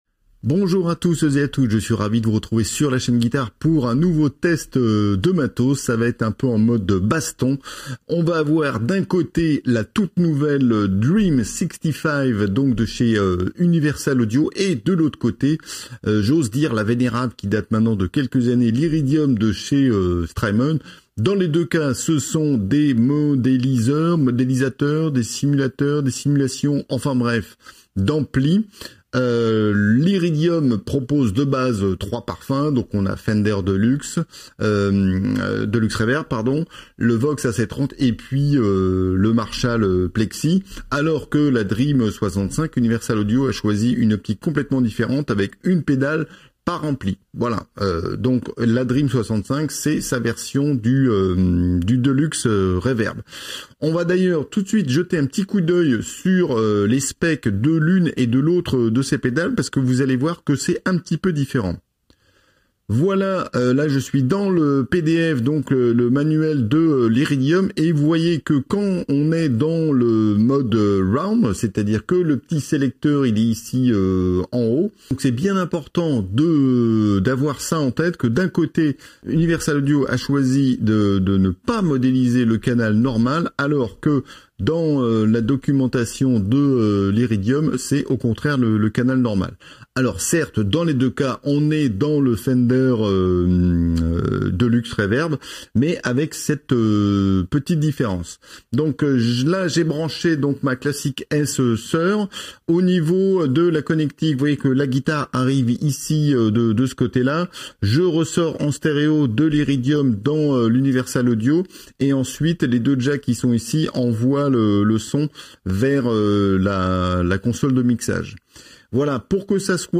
Baston de pédales entre la Dream '65 UAFX de chez Universal Audio et l'Iridium de Strymon : un Fender Deluxe au bout du pied.